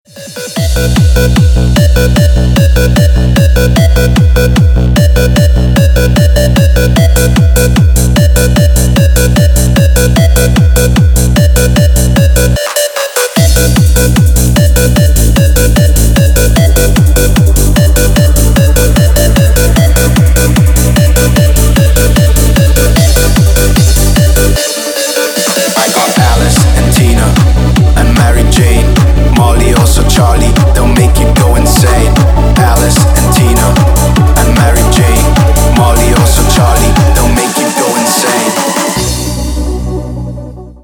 Бодрая нарезка на мобильный